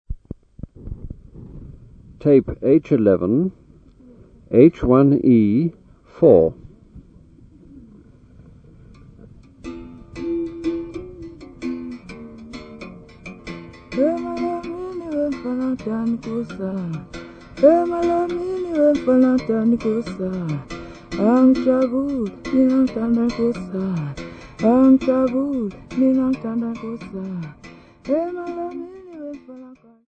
Folk music--Africa
Field recordings
Africa South Africa Mhlolutini Village f-sa
Love song with Makhweyana musical bow accompaniment.